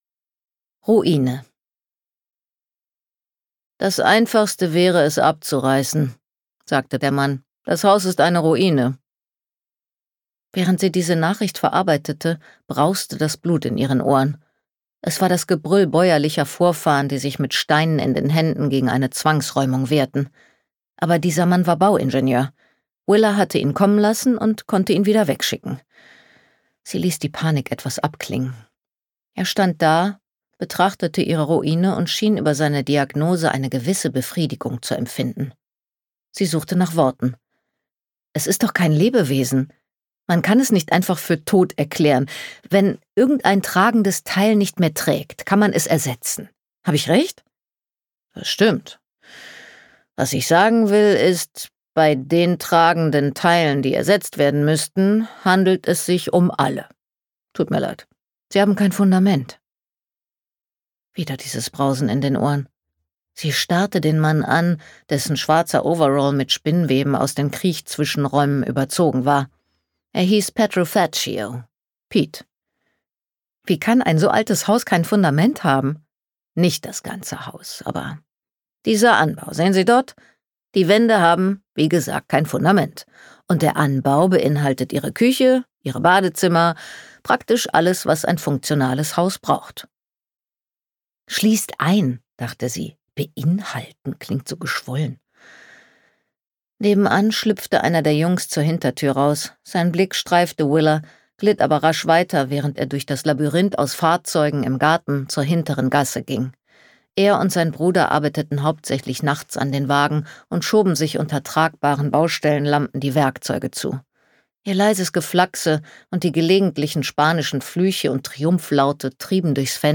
Die Unbehausten - Barbara Kingsolver | argon hörbuch
Gekürzt Autorisierte, d.h. von Autor:innen und / oder Verlagen freigegebene, bearbeitete Fassung.